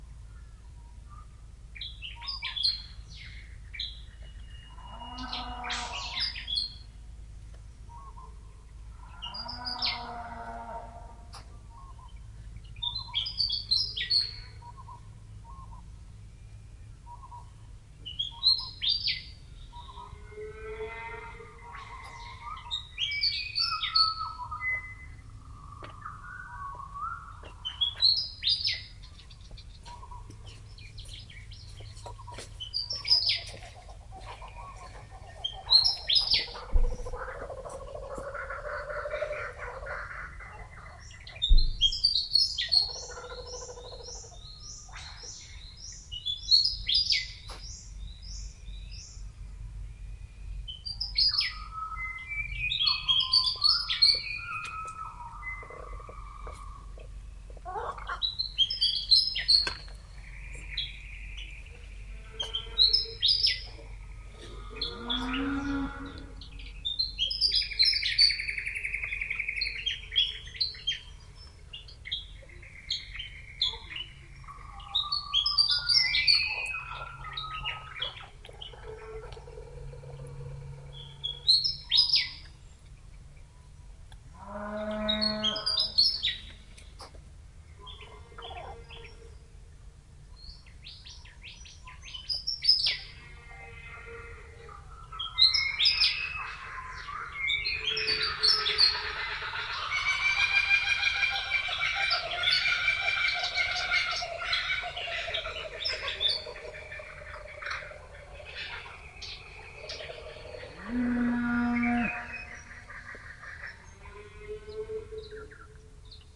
描述：2/3 奶牛，鞭子鸟和笑翠鸟（以及其他）的野外记录。小牛正在从母亲身上消瘦，所以有很多遥远和近乎呻吟。 在带有逼真PZM麦克风的Zoom H4n上录制。 Adobe Audition中的降噪功能
Tag: 鸣叫 动物的声音 景观 放牧 农业 养殖 牧场 农业 畜牧业 土地 农村 澳大利亚 热带 国家 农村 一般的噪声 农田